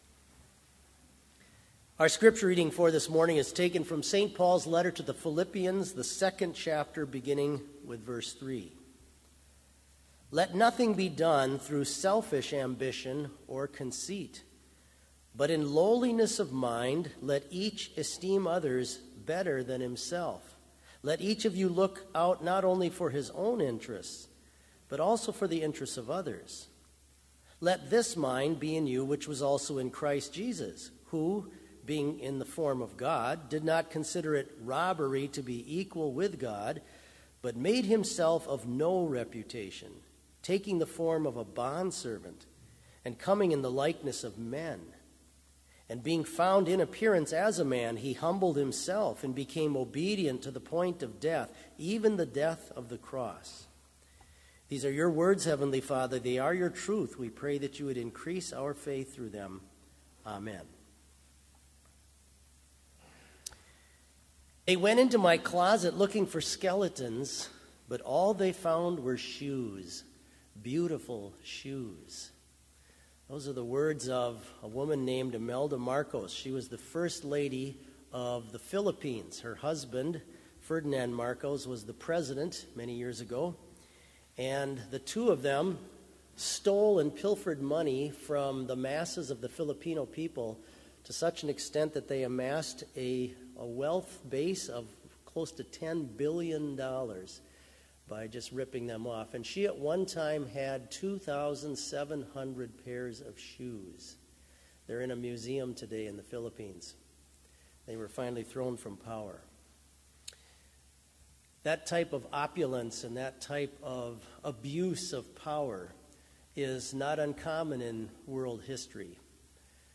Complete service audio for Chapel - December 7, 2018